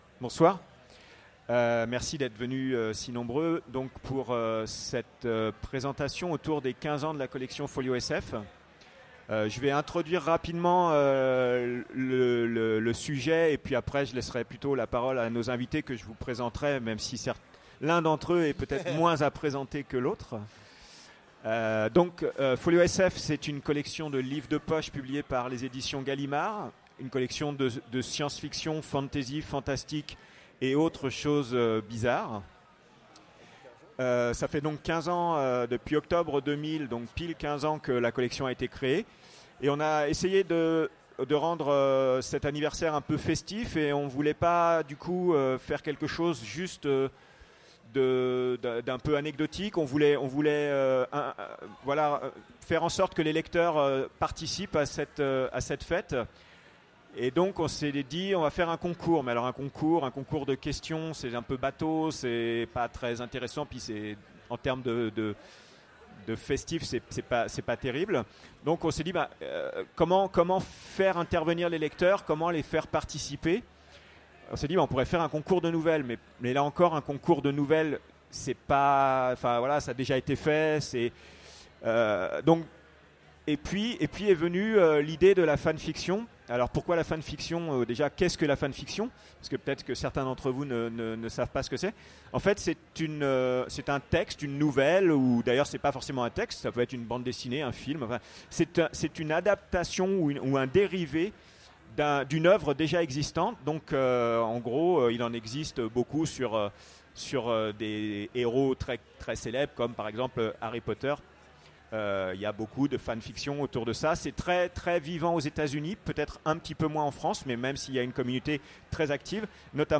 Utopiales 2015 : Conférence Folio SF, 15 ans de découverte
Conférence Folio SF